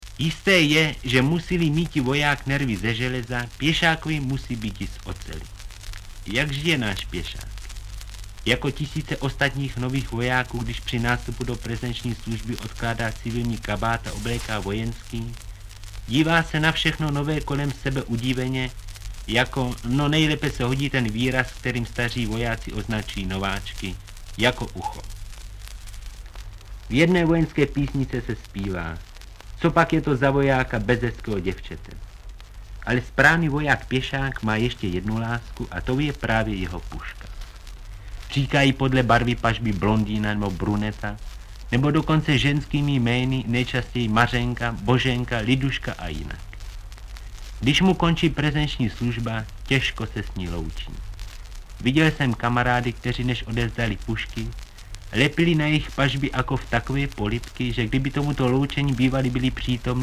Audiobook
Audiobooks » Theater, Radio, Television